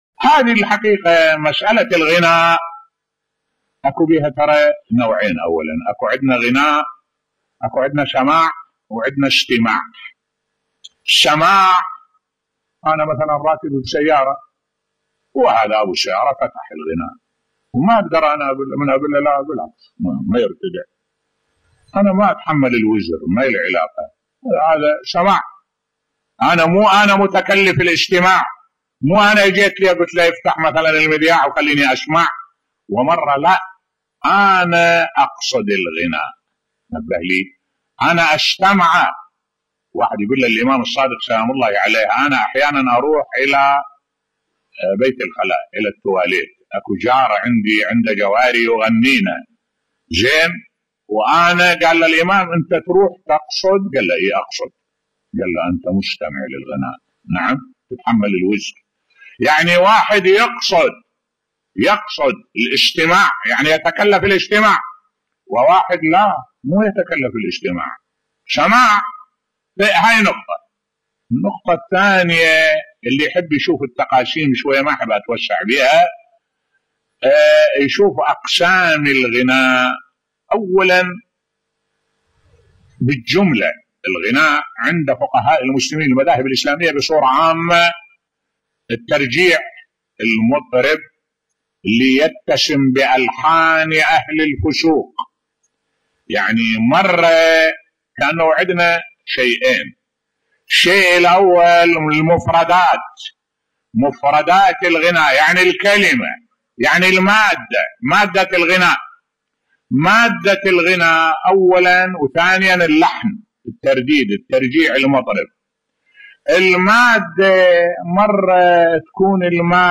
ملف صوتی الفرق بين استماعِ الغناء وسماعِه بصوت الشيخ الدكتور أحمد الوائلي